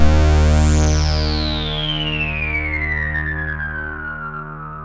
KORG D#2 1.wav